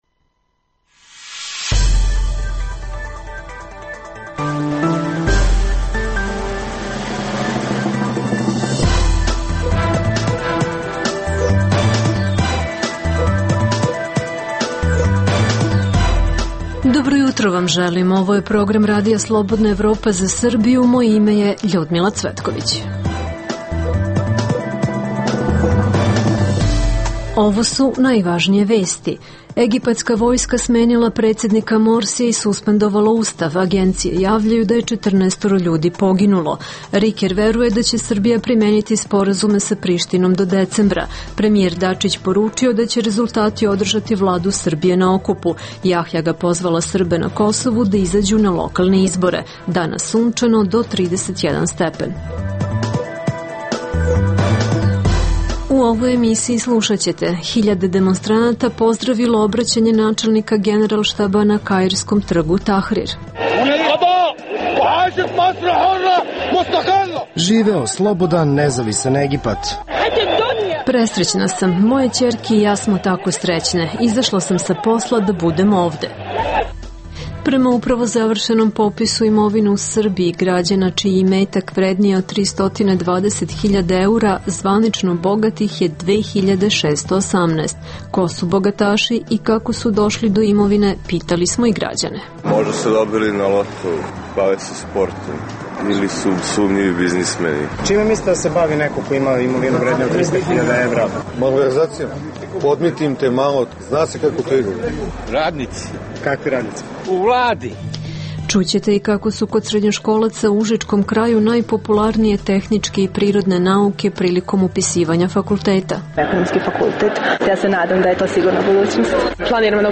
U emisiji poslušajte: - Egipatska vojska smenila predsednika Morsija, suspendovala Ustav. Čućete kako su hiljade demonstranata pozdravili obraćanje načelnika Generalštaba na kairskom trgu Tahrir, kao i prve reakcije svetskih zvaničnika i komentare analitičara.